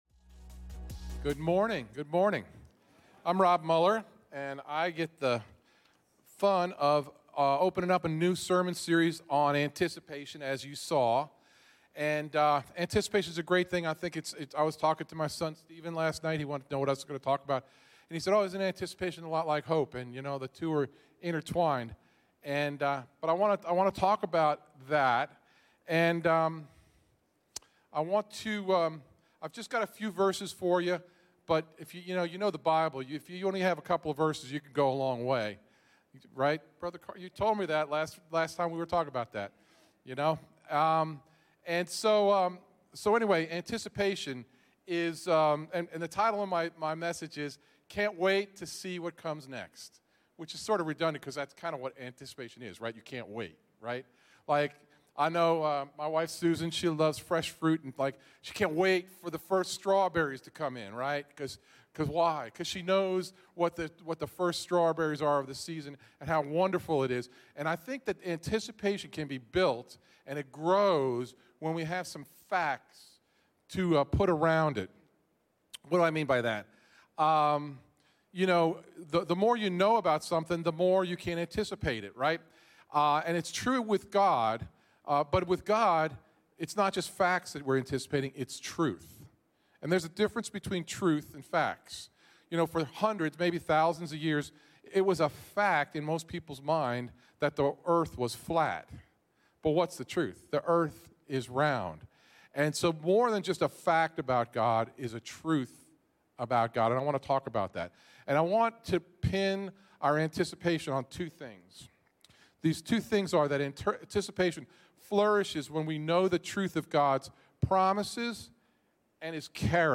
CTK-sermon.mp3